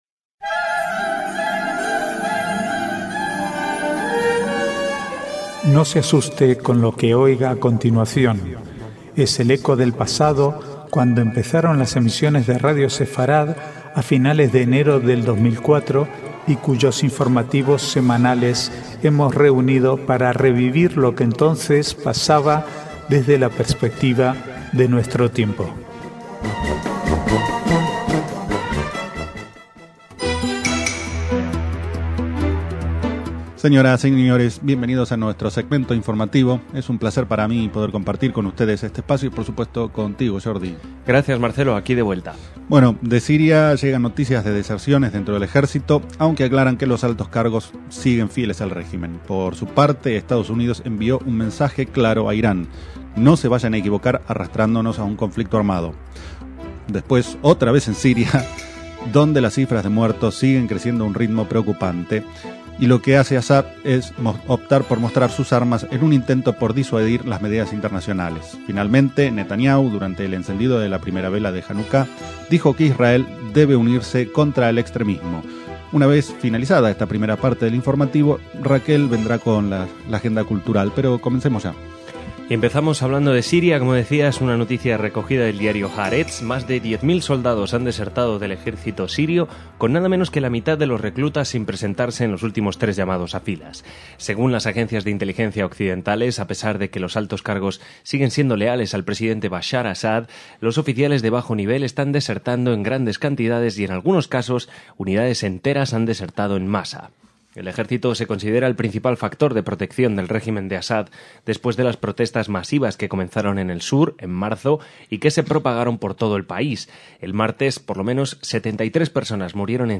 Archivo de noticias del 22 al 28/12/2011